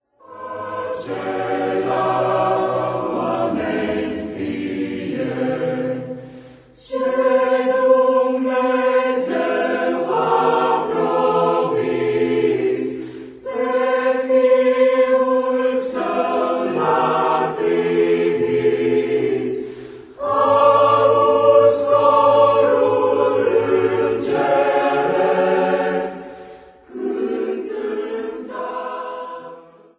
colinde traditionale